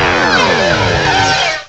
cry_not_dusknoir.aif